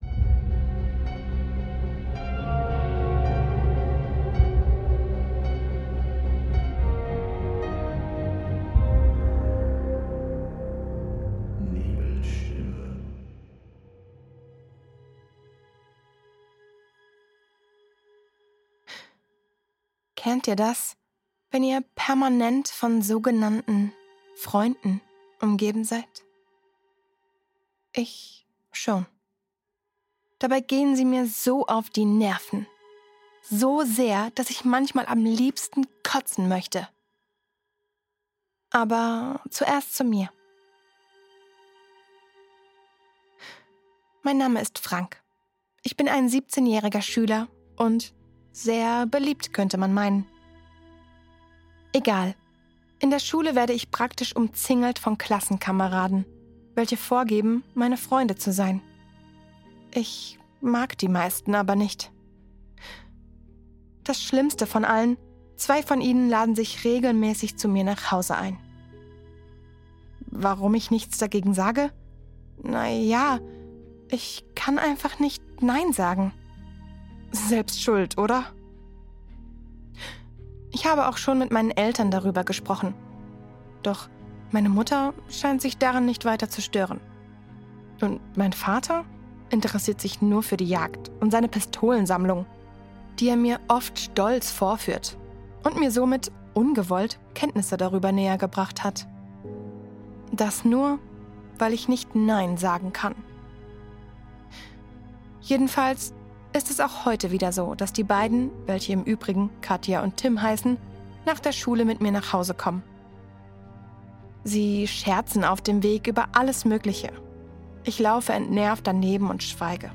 Manchmal sind es die stillen Antworten, die am lautesten hallen, wenn wir sie am wenigsten hören wollen. Du hörst hier die neu vertonte Version meiner früheren Aufnahme. Mit klarerem Sound, dichterer Atmosphäre und mehr Raum für das, was zwischen den Worten liegt.